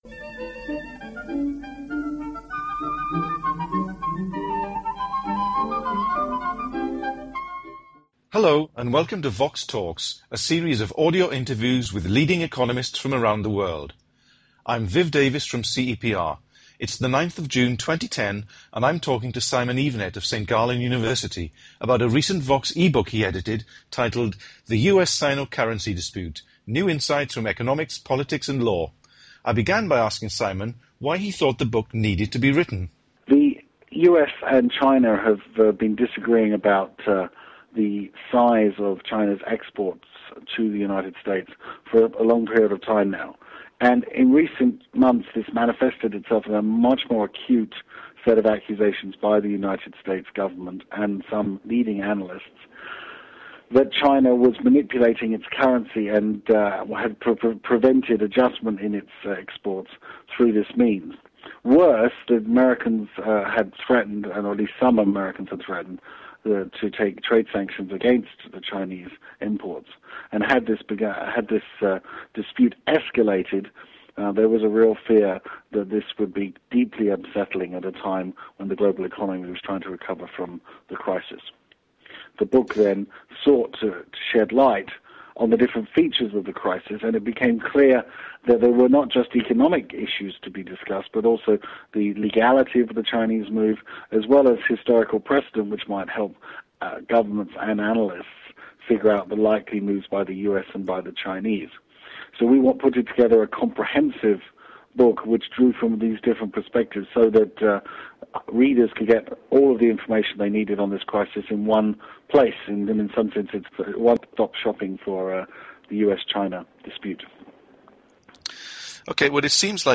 The interview was recorded in June 2010.